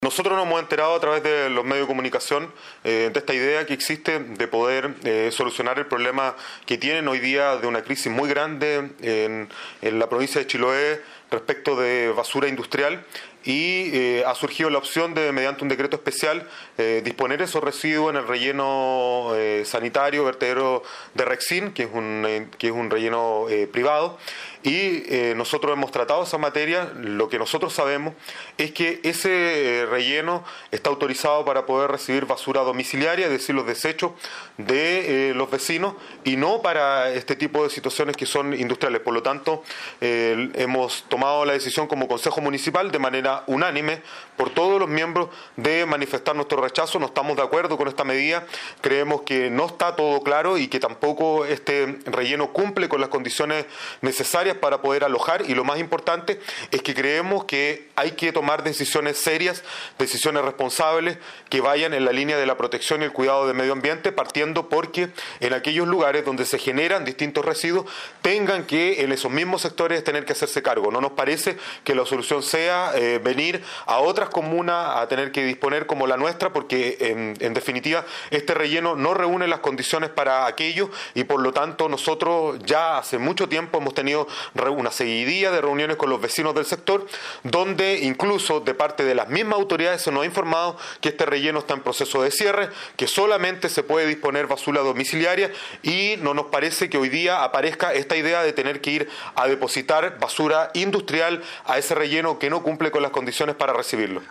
Así lo indicó el alcalde Jorge Westermaier.